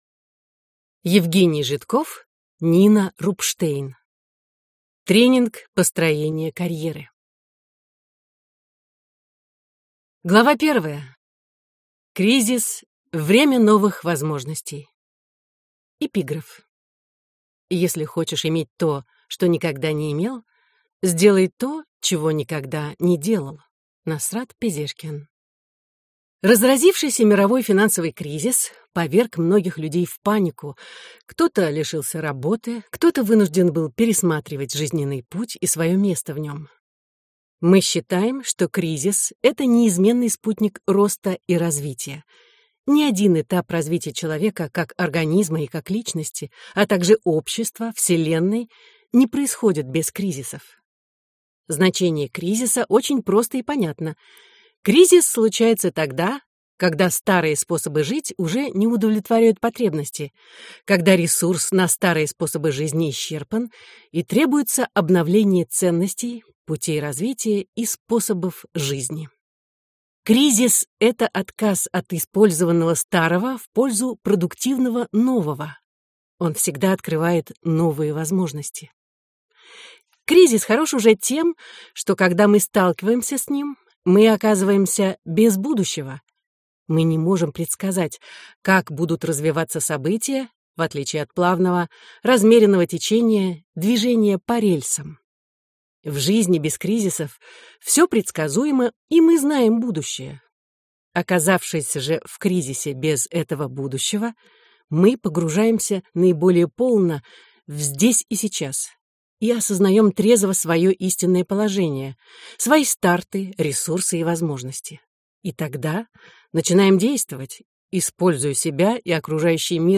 Аудиокнига Тренинг построения карьеры | Библиотека аудиокниг